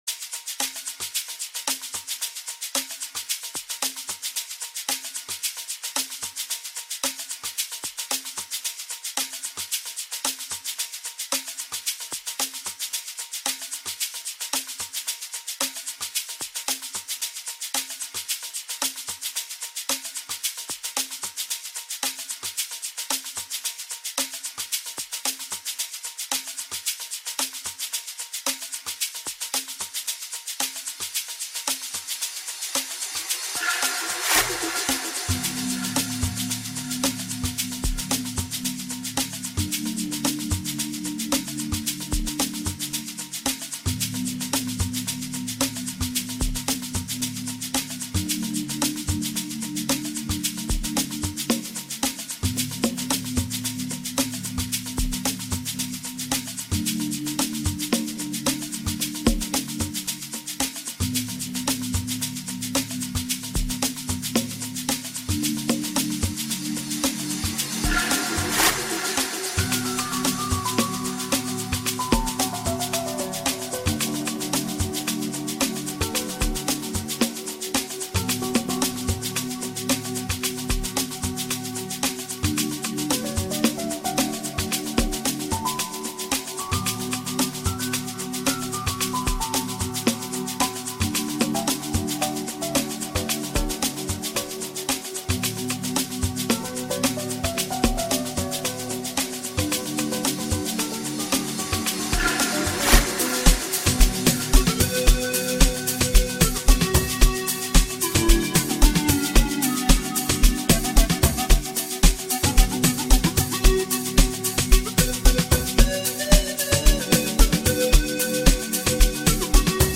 a full hour of Private School Amapiano